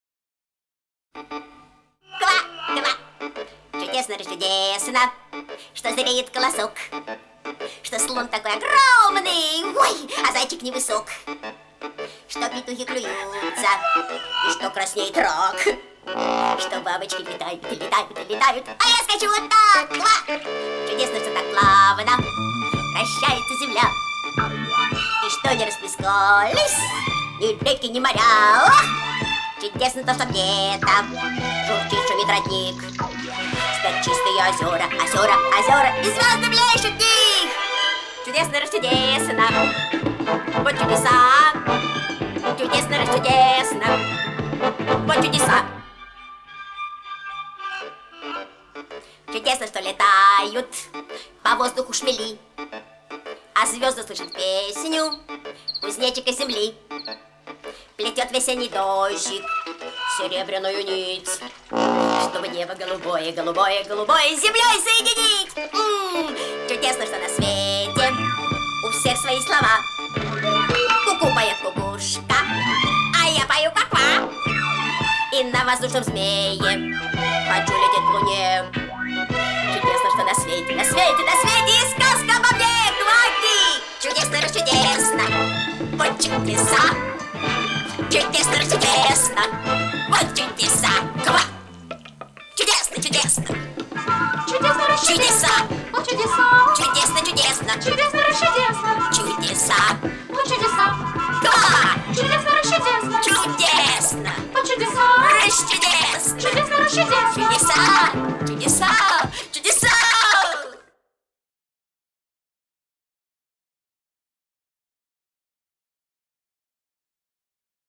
Главная / Песни для детей / Песни из детских фильмов